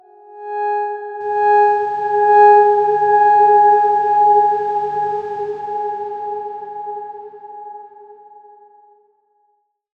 X_Darkswarm-G#4-pp.wav